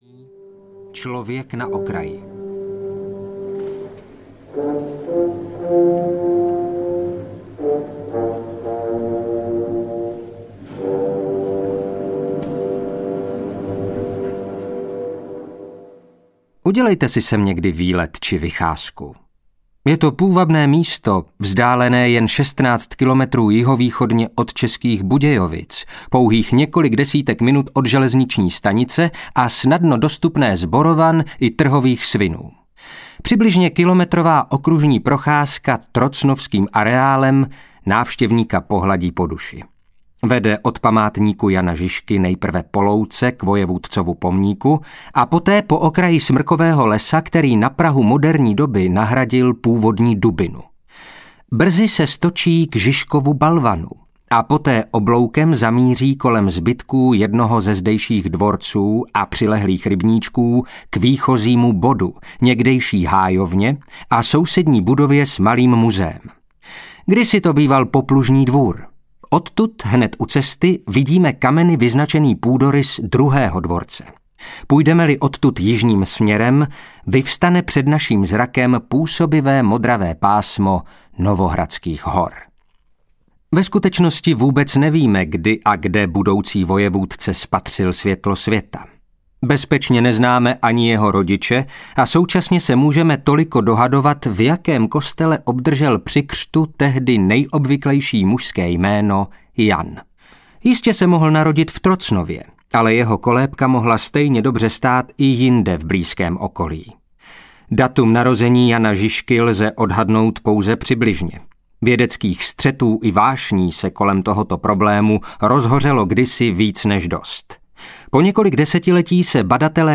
Jan Žižka audiokniha
Ukázka z knihy